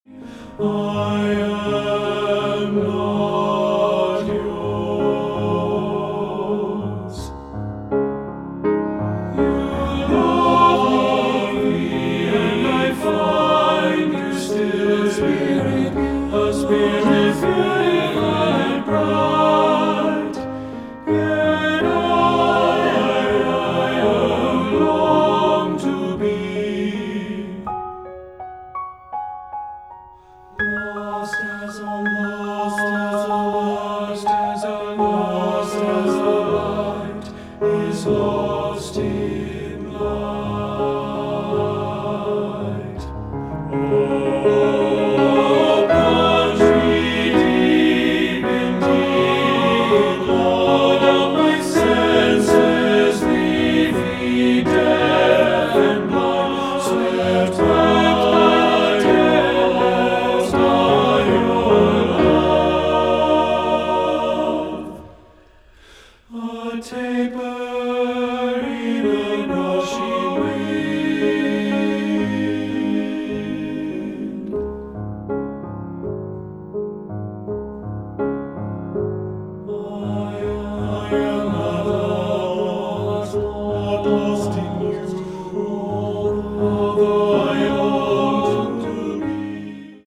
Choral Male Chorus